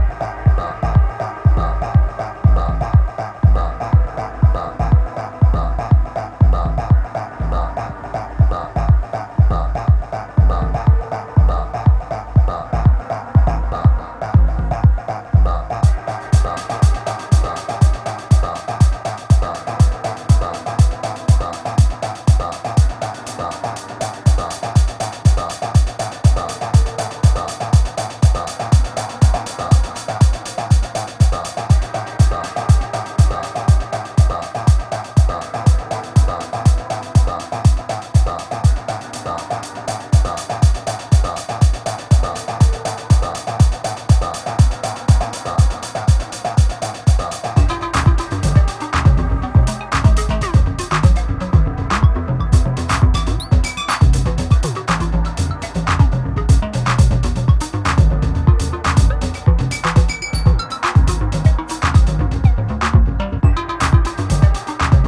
Electro Techno Soul Detroit